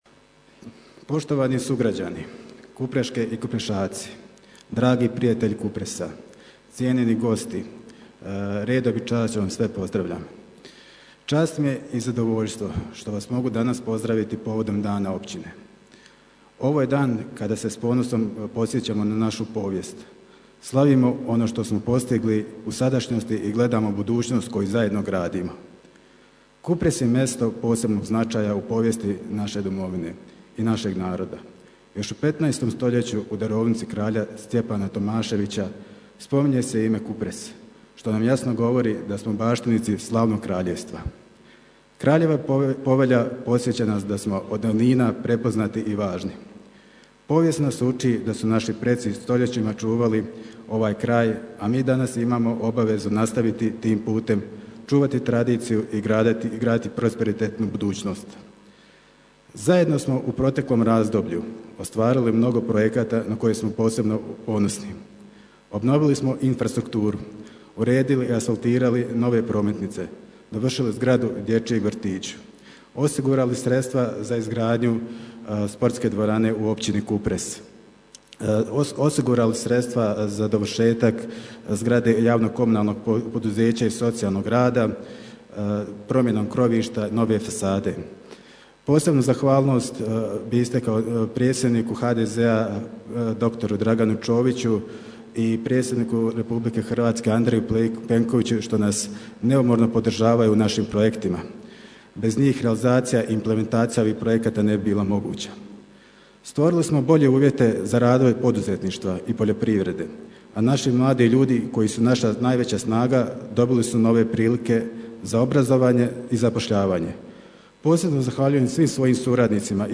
Potom i g. Danko Jurič Načelnik Općine nakon video prezentacije o realiziranim projektima u našoj Općini koji se i riječima osvrnuo na važnije projekte koji su realizirani i koji se realiziraju na prostoru općine Kupres kao i onoga što se još treba uraditi.
Načelnik_Danko_Jurič_dan_općine_.2025.mp3